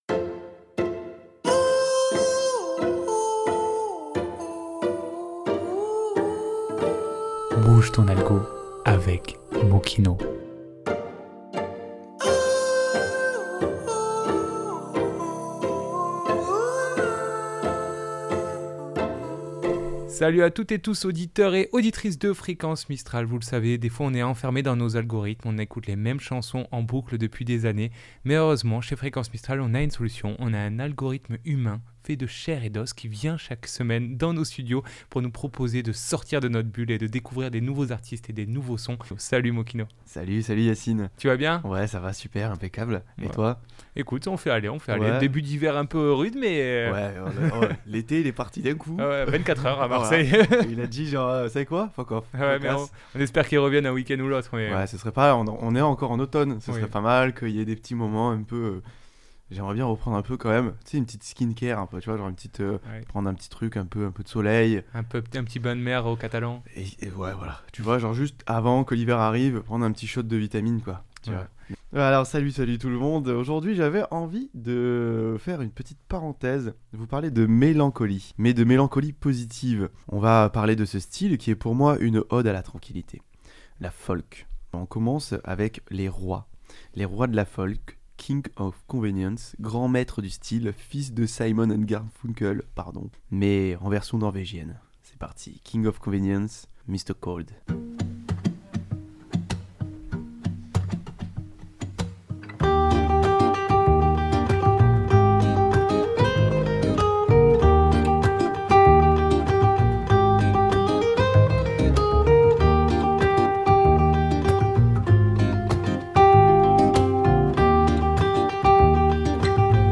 Emission spéciale "Douce Folk" .